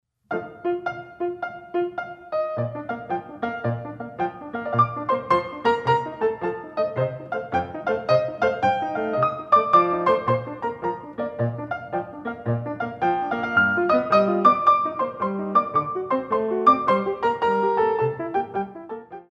Warm-Up Allegro In 6/8